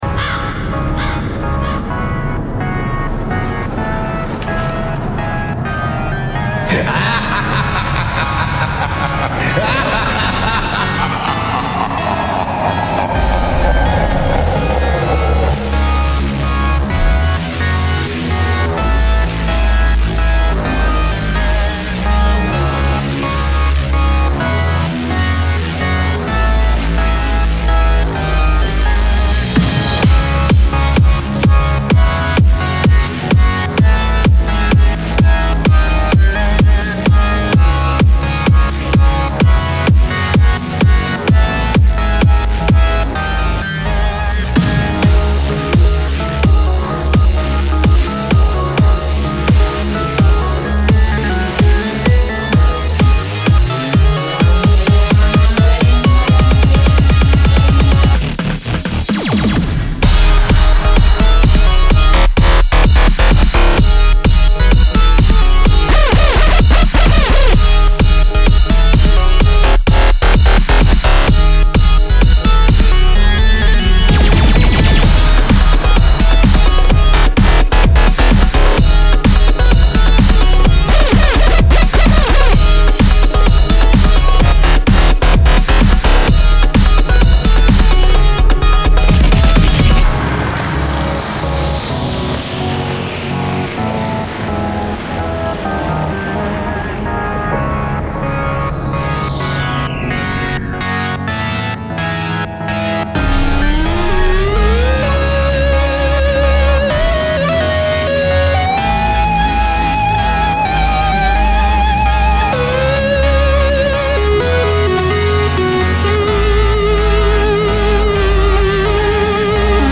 BPM128-128
Audio QualityPerfect (High Quality)
Full Length Song (not arcade length cut)